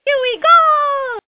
One of Princess Peach's voice clips in Mario Kart: Super Circuit